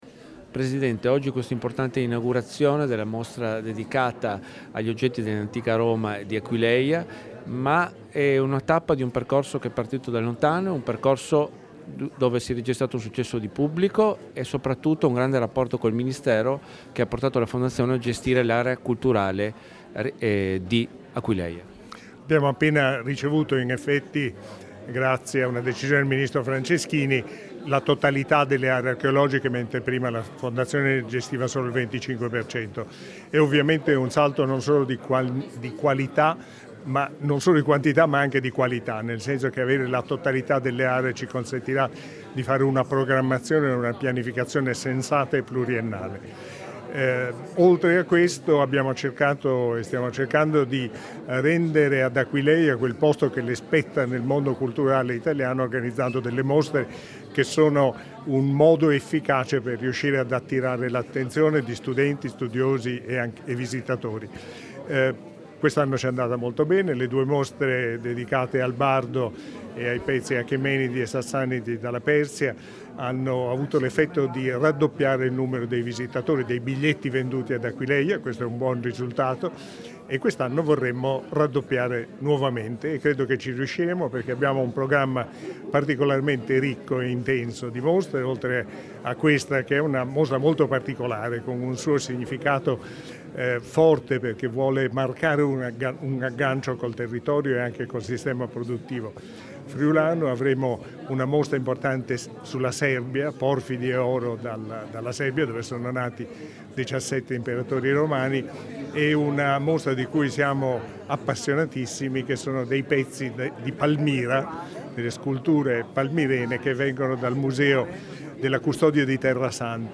Dichiarazioni di Antonio Zanardi Landi (Formato MP3) [3605KB]
all'inaugurazione della mostra "Made in Roma and Aquileia", rilasciate ad Aquileia l'11 febbraio 2017